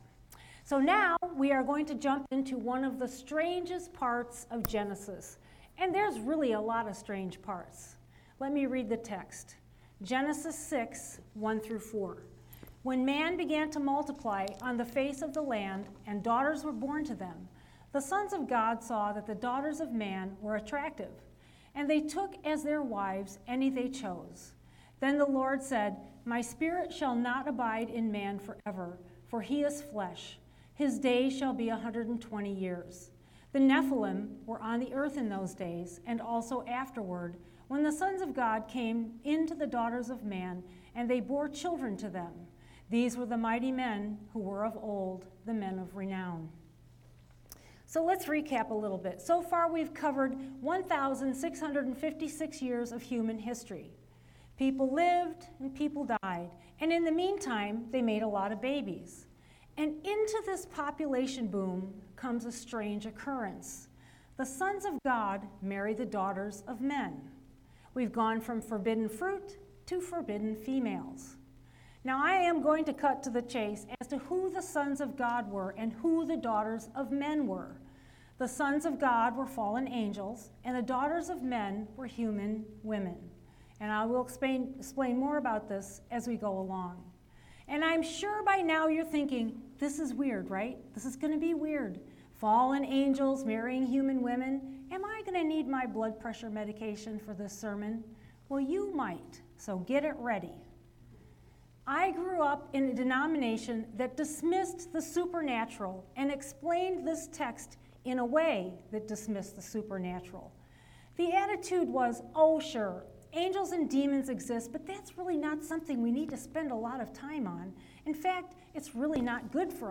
The Nephilim – Faith Wesleyan Church